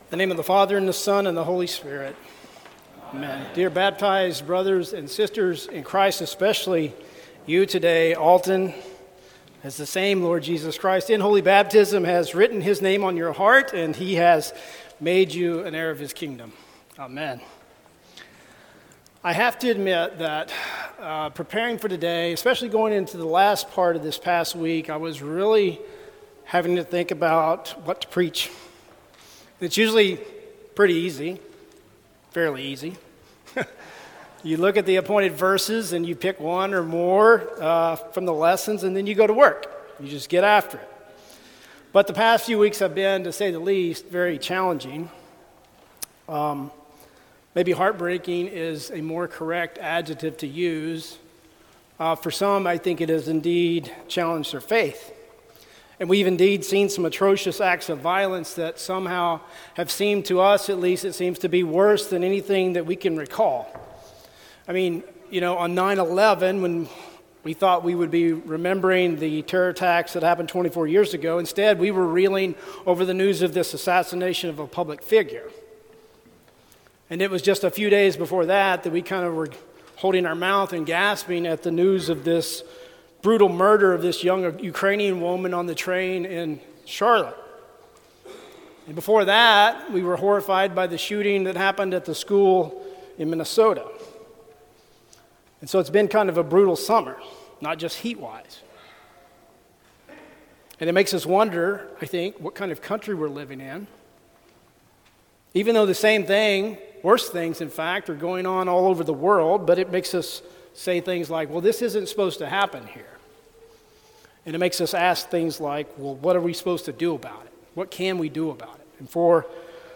Sermon for Fourteenth Sunday after Pentecost